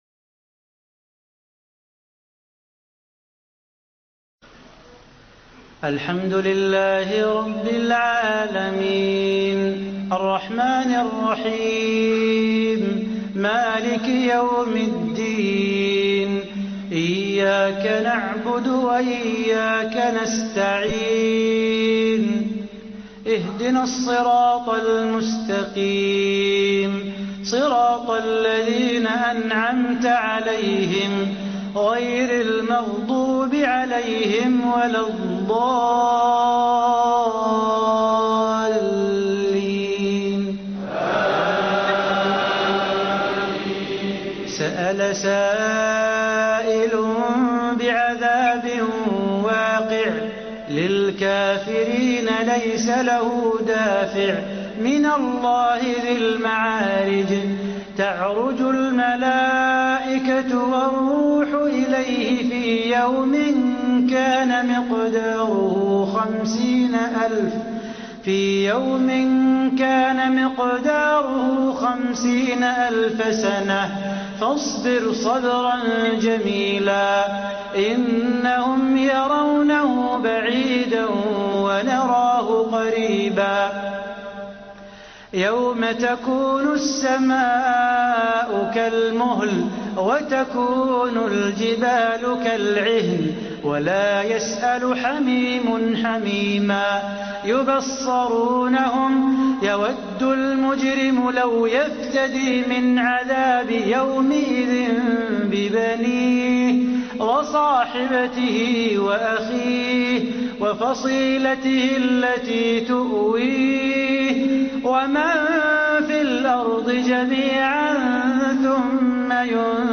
#5: Salah Bukatir #4: Salah Al Budair #3: Abdel baset Abdul Samat #2: Abdul Rahman Al Sudais #1: Su'ud shuraim
Coming in at number five is Salah Bukatir, the Tunisian reciter whose voice carries a haunting, melodic quality that has earned him a global following through the internet generation — his recitations circulate widely on YouTube and social media, introducing millions of young Muslims to the beauty of the Qur’an. At number four, Salah Al-Budair, the Imam of Masjid al-Nabawi in Madinah, brings an authority and spiritual weight to his recitation that befits leading prayers in the most beloved mosque on Earth.